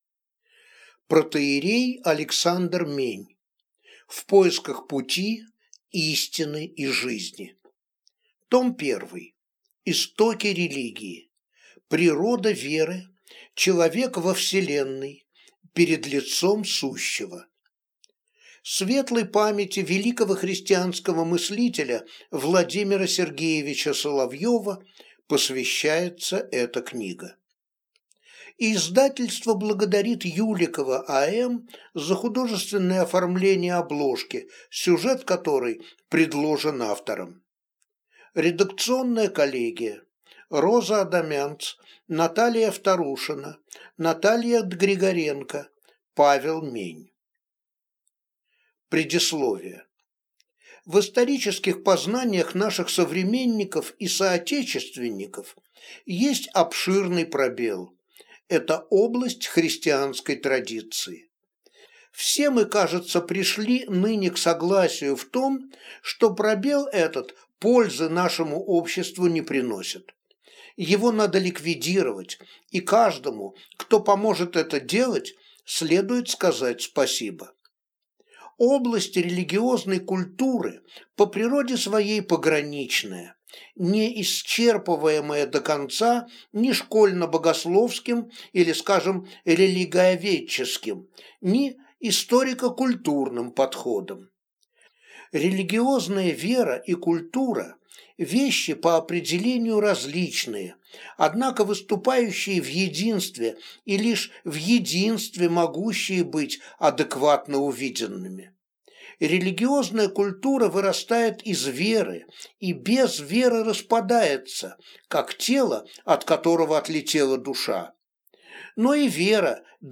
Аудиокнига В поисках Пути, Истины и Жизни.